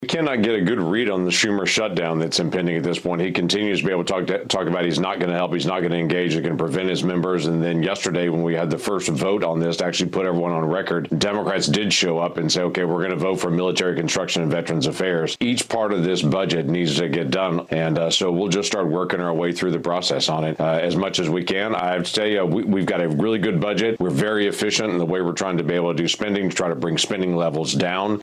On Wednesday morning, Oklahoma Senator James Lankford spoke to Fox Business on a looming government shutdown that is just over 60 days away.